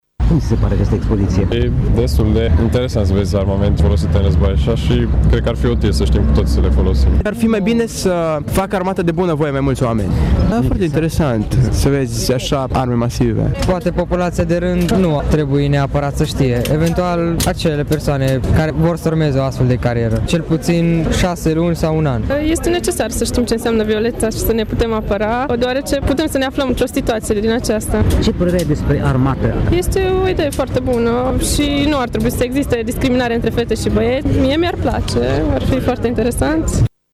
În Piaţa Trandafirilor, a avut loc cu această ocazie o expoziţie de armament, materiale, echipamente şi aparatură specifică din dotarea instituţiilor din Sistemului Naţional de Apărare, Ordine Publică şi Securitate din plan local.
Târgumureșenii, tineri sau maturi cred că nu e necesar ca toți oamenii să cunoască mânuirea armelor, însă e bine ca tinerii să facă armata: